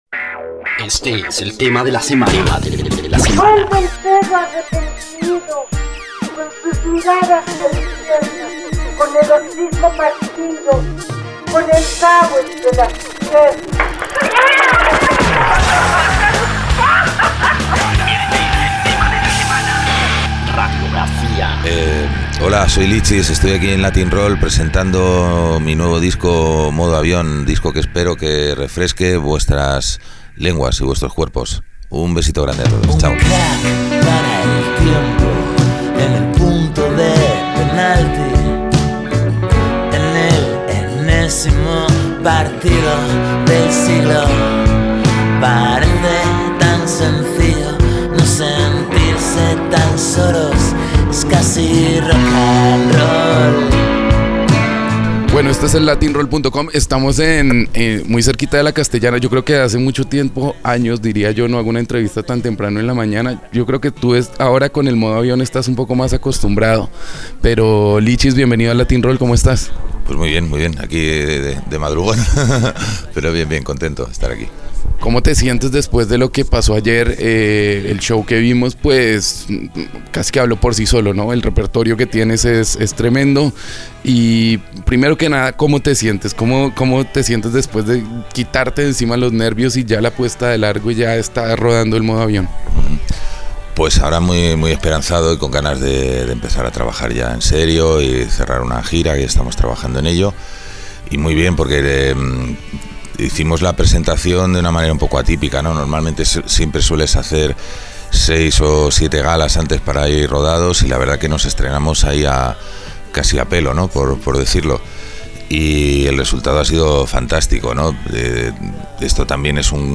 Latin-Roll - Entrevistas